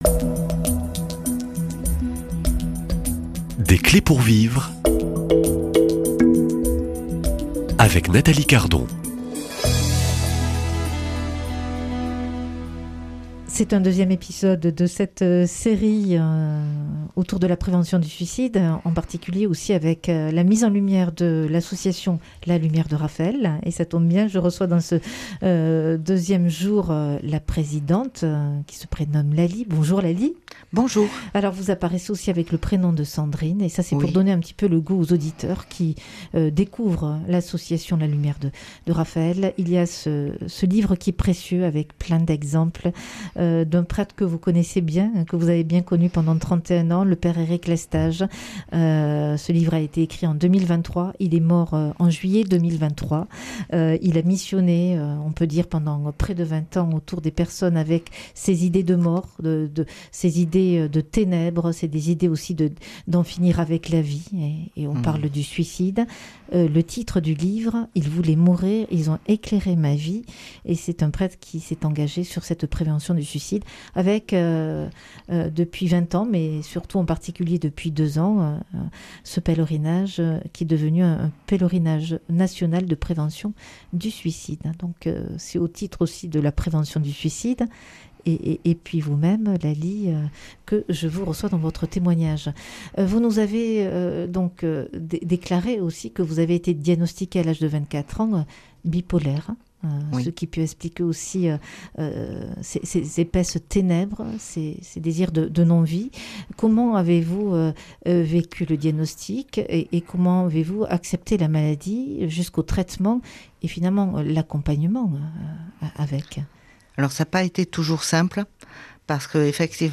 Comment on combat ? Comment ne pas se tromper de combat ? Comment guérir ? Invitée